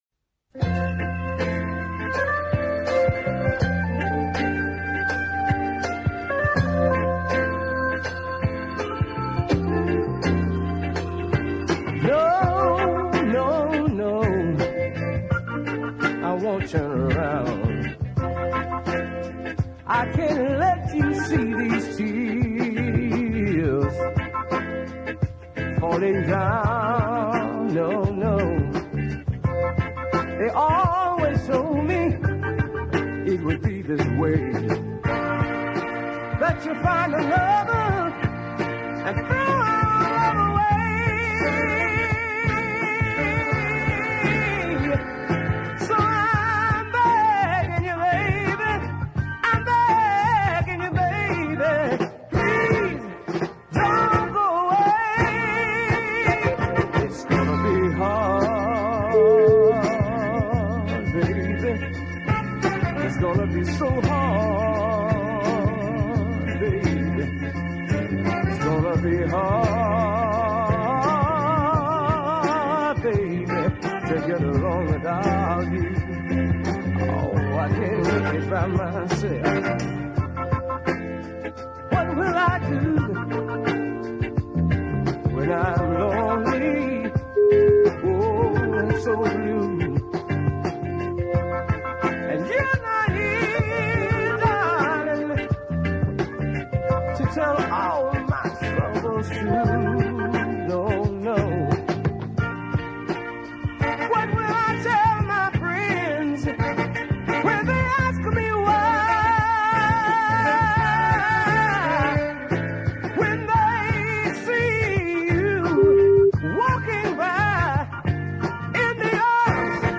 superior southern soul mid pacer